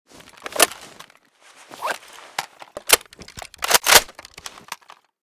ash12_reload_empty.ogg.bak